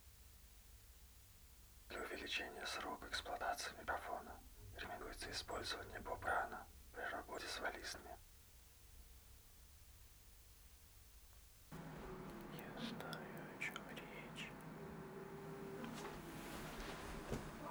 Ленточный микрофон "АПЛ" (Алюминиевая-Поталевая-Лента) лента 0,2 микрона.
Так же записал свой шёпот, подогнал уровень к вашей записи. Сначала идёт ваша, следом моя одним файлом. У меня кстати шумнее в комнате, ноутбук в двух метрах за спиной - следовательно "только шёпот" на картинке не будет так сладко красоваться.
Вложения два шёпота.wav два шёпота.wav 1,5 MB · Просмотры: 123